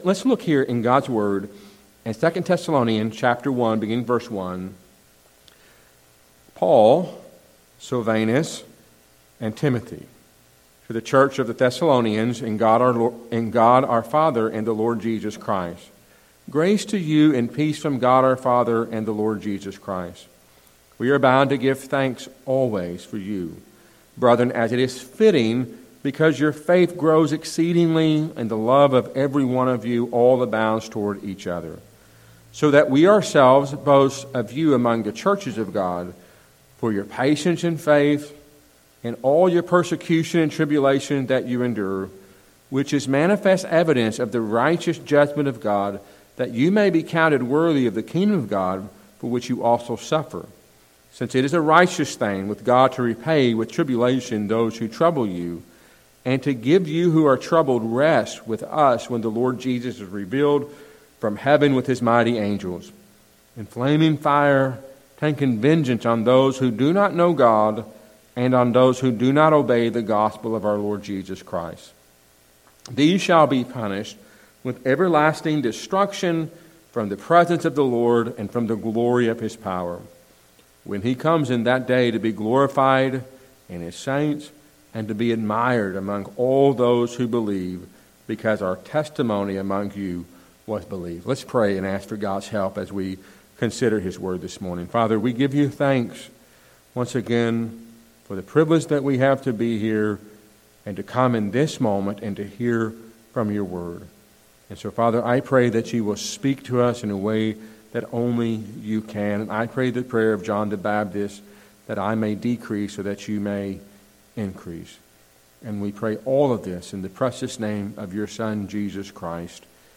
A sermon from 2 Thessalonians 1:1-10.